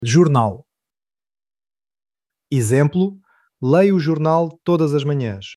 Pronunciación de la palabra